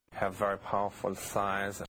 Bruno Movie Sound Bites